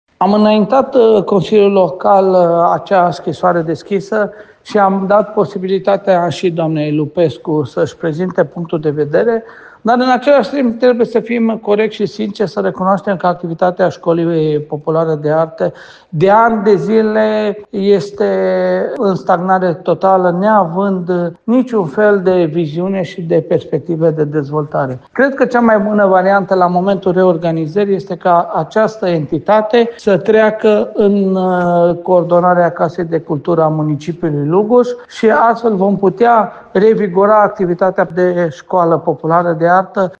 Călin Dobra, primarul Lugojului, spune că a luat decizia comasării instituției cu Casa de Cultură pentru a revigora activitatea.